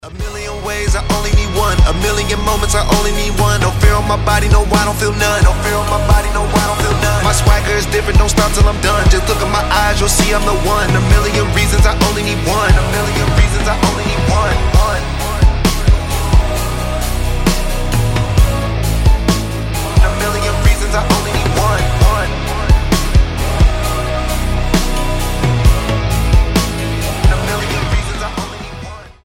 • Качество: 320, Stereo
мужские
Рэп-рок